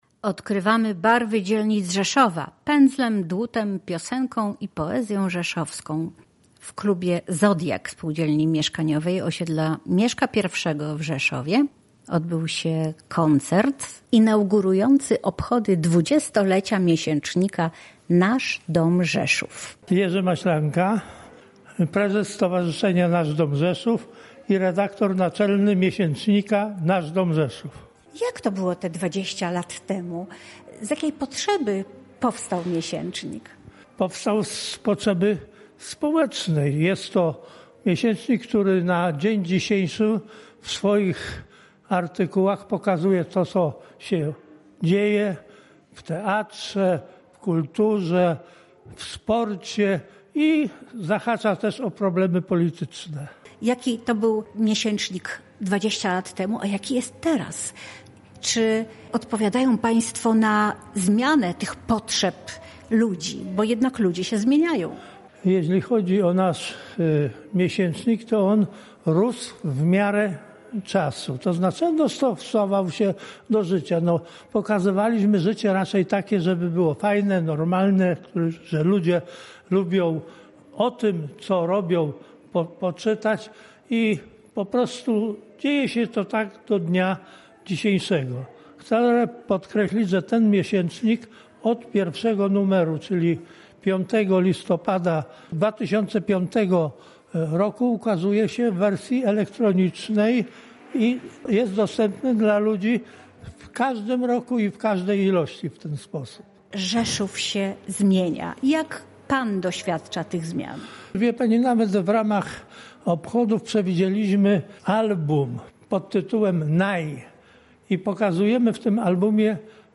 Podczas koncertu wystąpił Chór Cantilena UTW UR im. Alicji Borowiec, który zachwycił słuchaczy wykonaniem piosenek i przyśpiewek rzeszowskich.